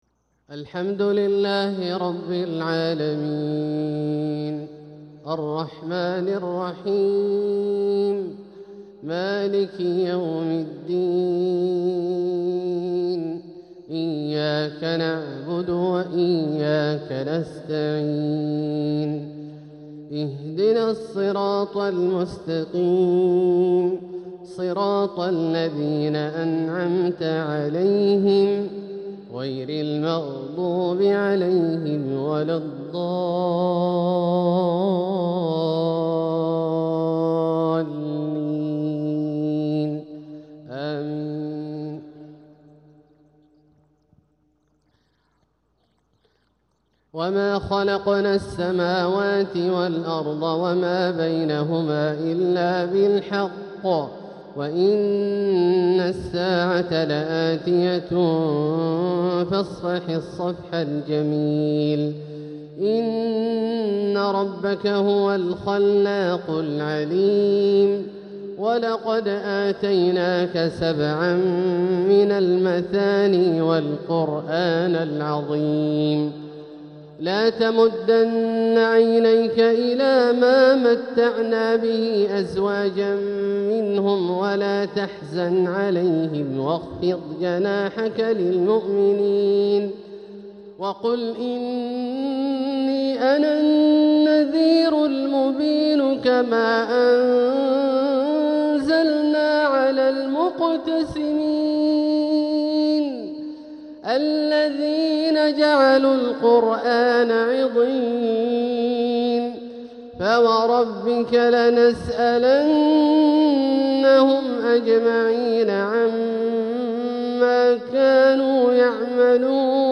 تلاوة لخواتيم سورة الحجر 85-99 | مغرب الإثنين 10 صفر 1447هـ > ١٤٤٧هـ > الفروض - تلاوات عبدالله الجهني